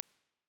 CiderSpatial_Natural.wav